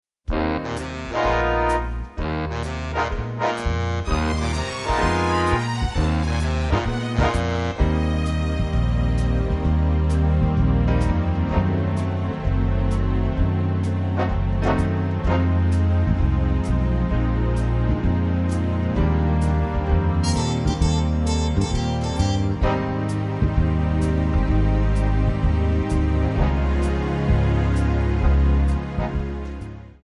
Eb
MPEG 1 Layer 3 (Stereo)
Backing track Karaoke
Pop, Oldies, Jazz/Big Band, 1950s